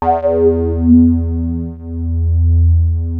JUP 8 E3 6.wav